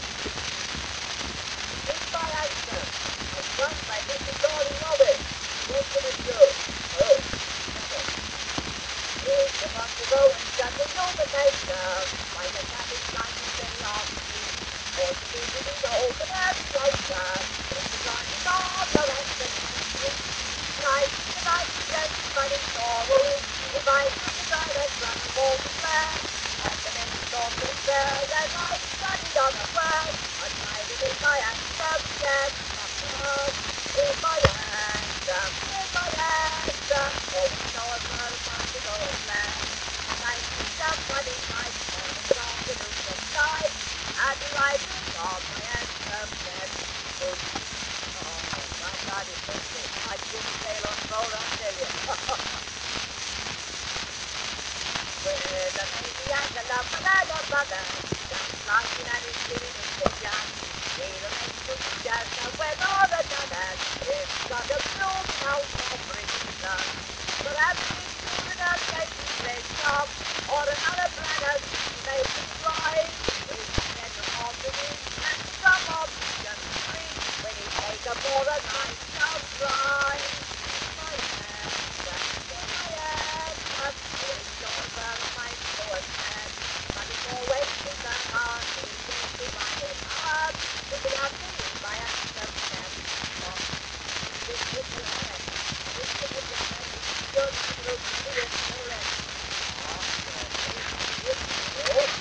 Unbekannter Sänger